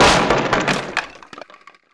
q3rally/baseq3r/sound/breakable/wood.wav at 8c2183bd44213c02eab769bece9431c194c6d048
wood.wav